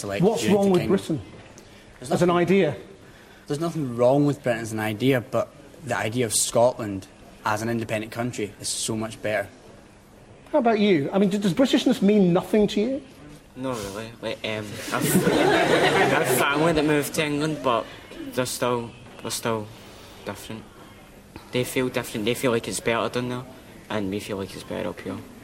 Debate on independence, Channel 4 News, 10 September 2014